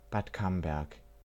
Bad Camberg (German: [baːt ˈkambɛʁk]